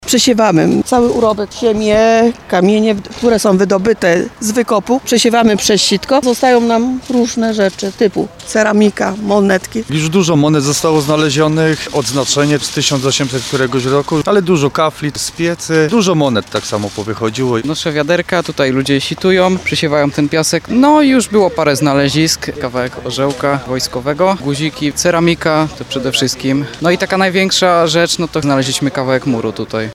5sonda_zamek.mp3